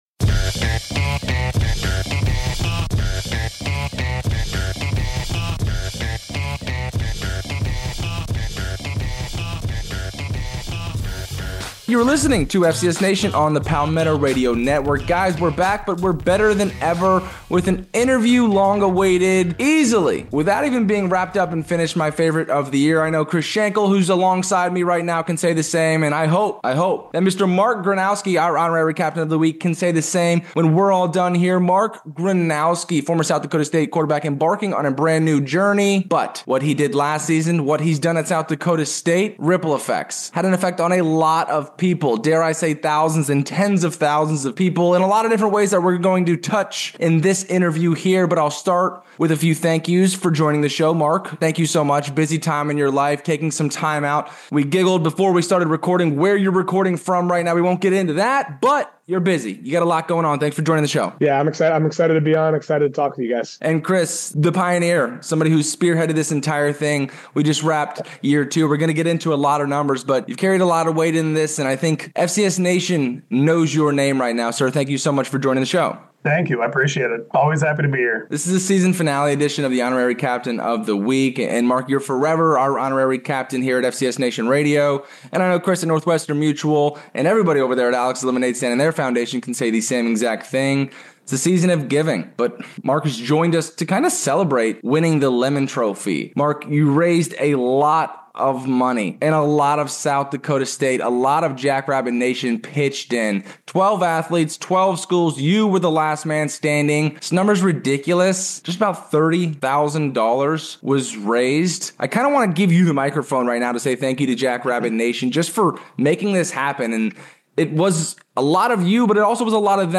Join us for an unforgettable interview